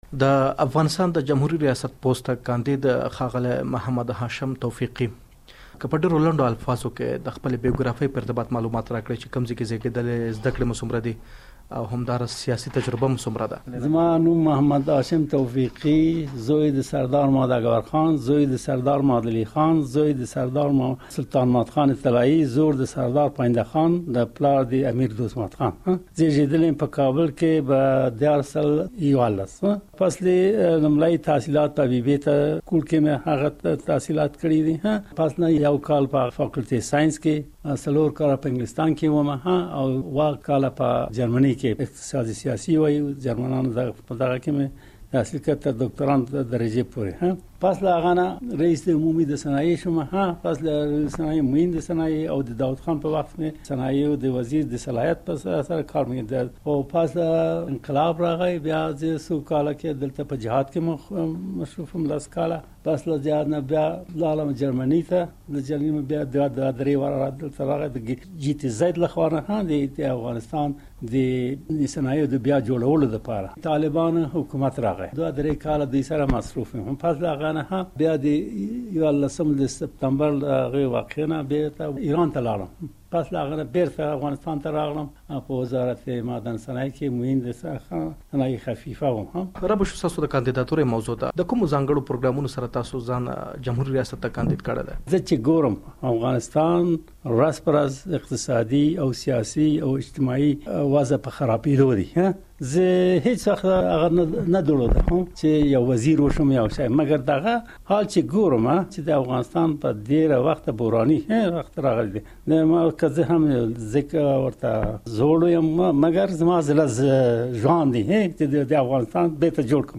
ځانګړې مرکه واورﺉ